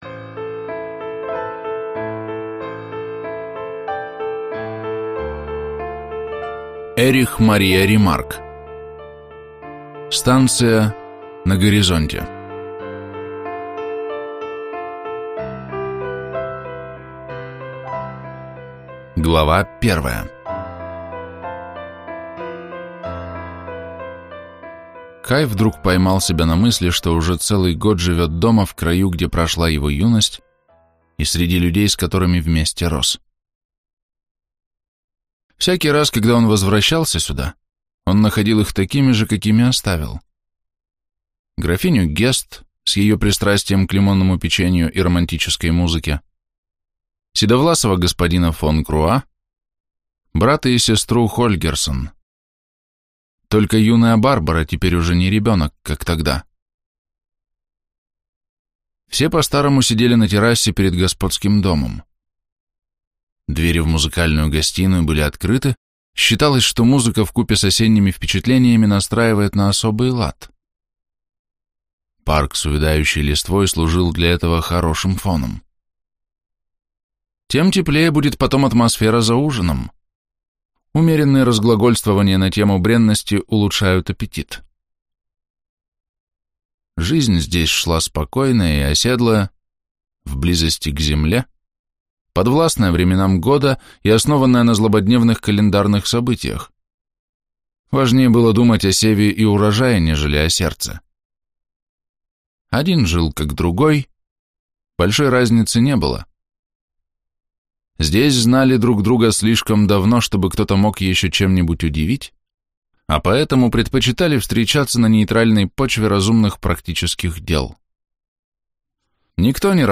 Аудиокнига Станция на горизонте - купить, скачать и слушать онлайн | КнигоПоиск